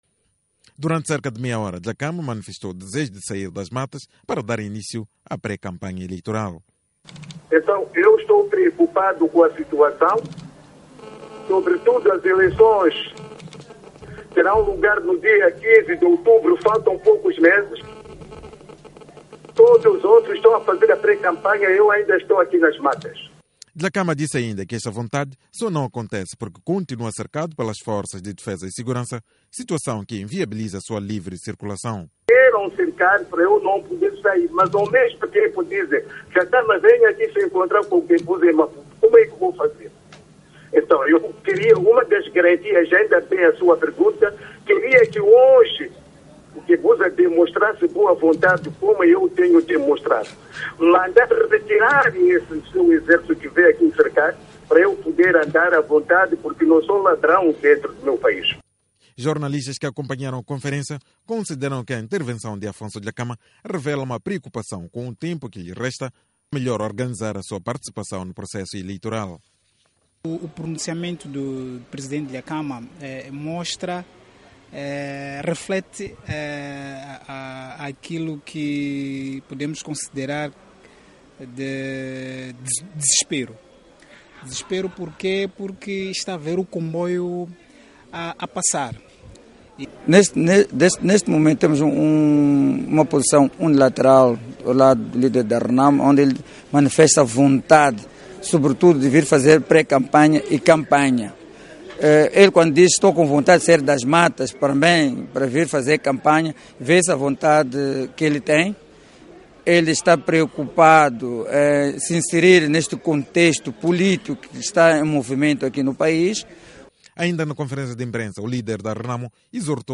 Dezenas de jornalistas acorreram à sede da Renamo, em Maputo, para uma conferência de imprensa telefónica de Afonso Dhlakama